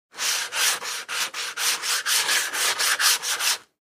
in_sandpaper_03_hpx
Wood being sanded by hand. Tools, Hand Wood, Sanding Carpentry, Build